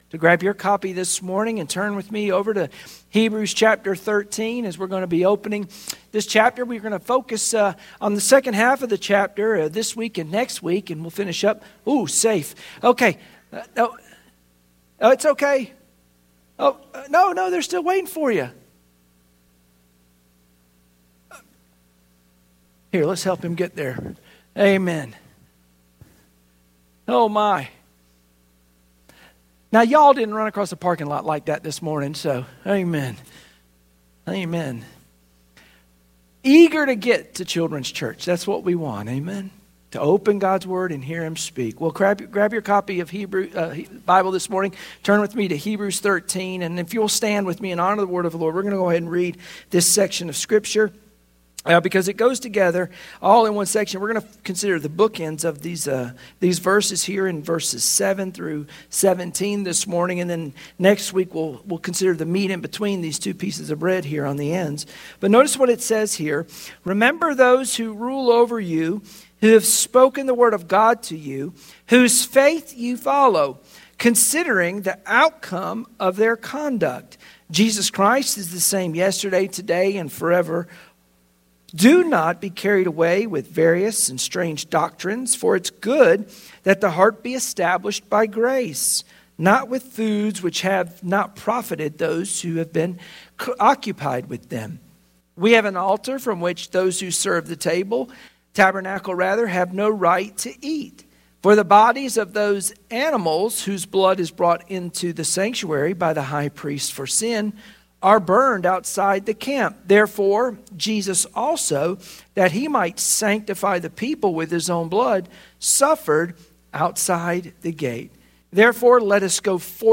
Sunday Morning Worship Passage: Hebrews 13:7,17 Service Type: Sunday Morning Worship Share this